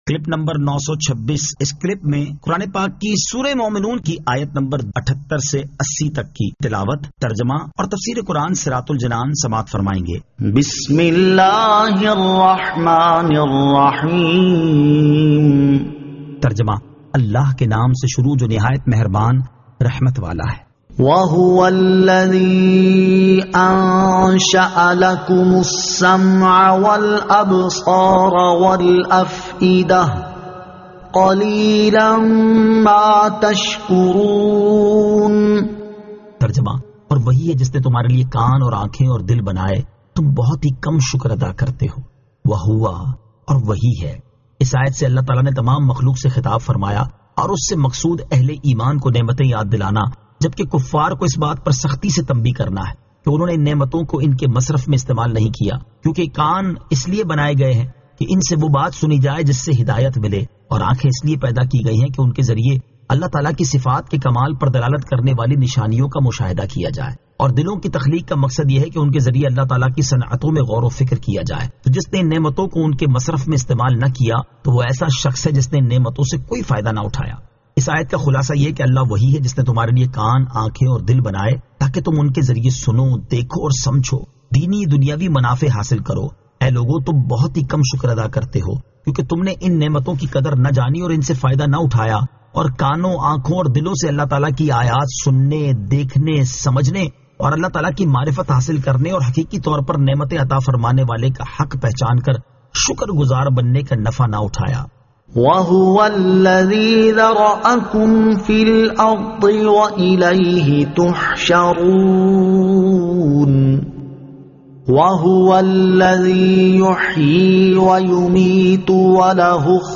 Surah Al-Mu'minun 78 To 80 Tilawat , Tarjama , Tafseer